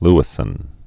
(lĭ-sən)